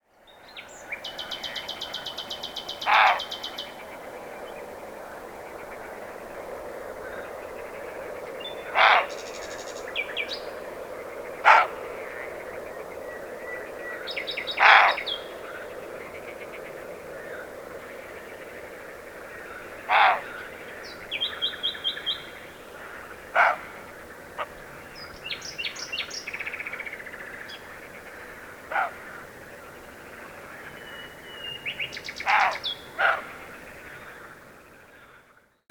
While I was waiting in the dark a European Roe Deer Capreolus capreolus came closer unnoticed and barked as it was frightened by my presence. Nearby a Common Nightingale Luscinia megarhynchos was singing in the dark too.
roe-dee_capreolus-capreolus.mp3